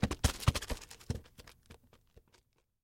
Звуки капусты
Капуста катится по траве